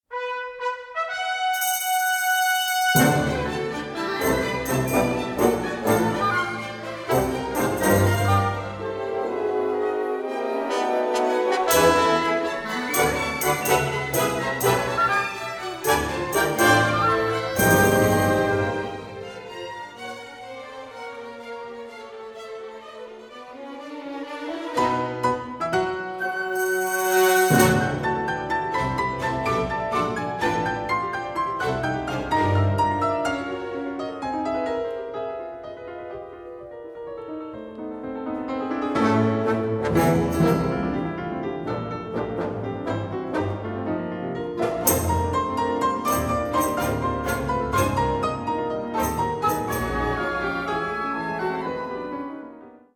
3 Allegro